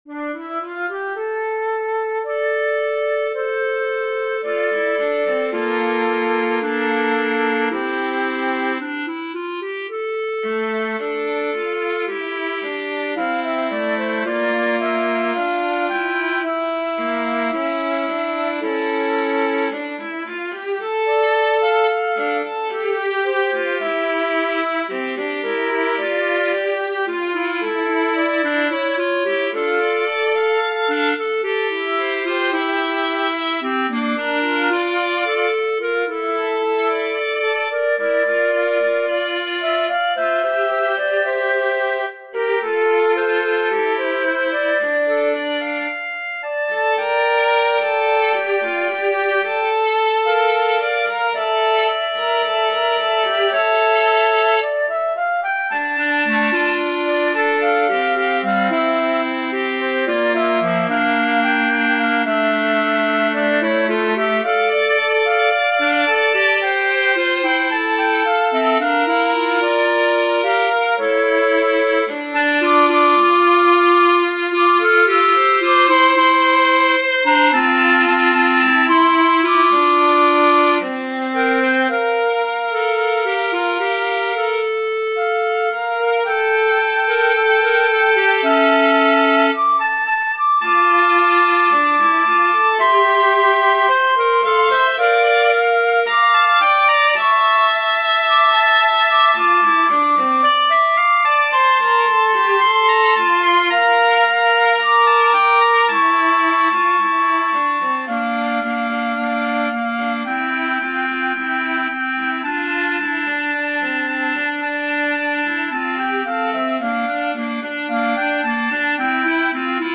Flute, Clarinet, Viola